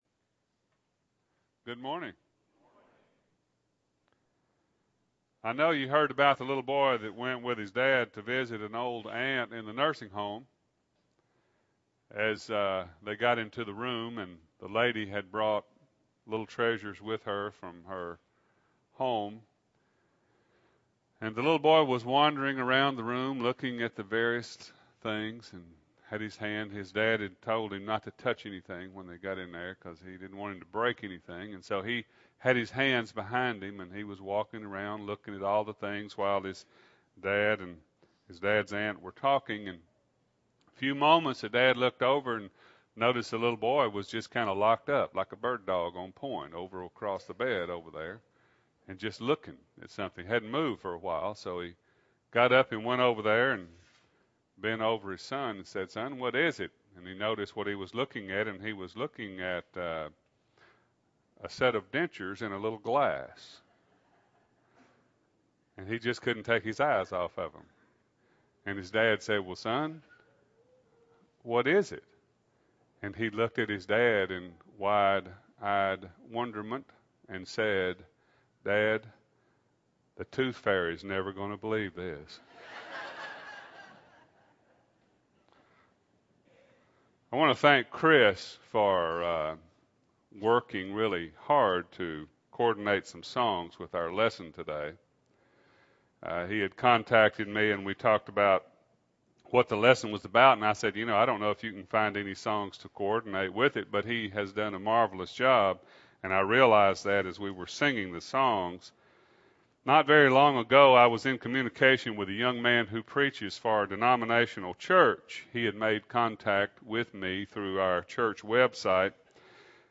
2008-04-27 – Sunday AM Sermon – Bible Lesson Recording